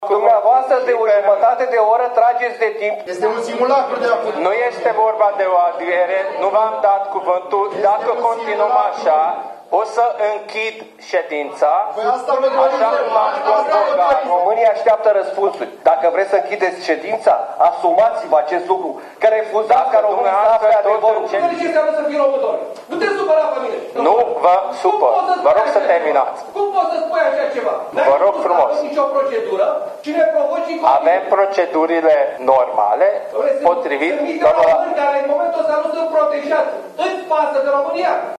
Scandal în Comisia de Politică Externă a Camerei Deputaților, în care este audiată ministra de Externe.
11mar-16-cearta-Adrian-Caciu-si-Hajdu-Gabor-.mp3